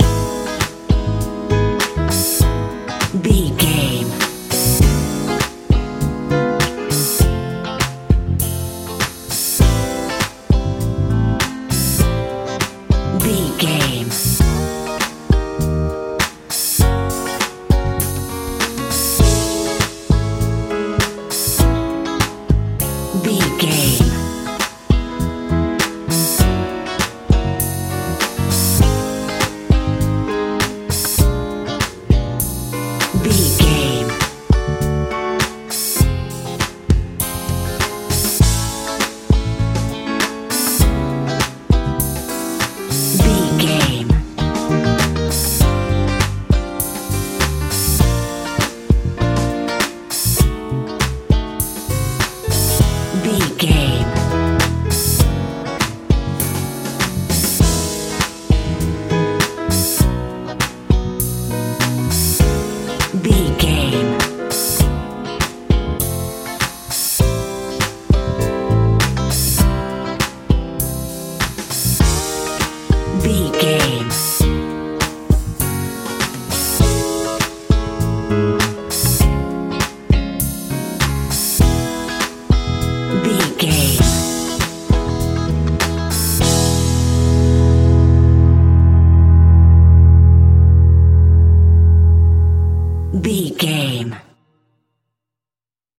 modern rnb feeling music
Ionian/Major
groovy
piano
electric guitar
bass guitar
drums
driving
energetic
joyful
playful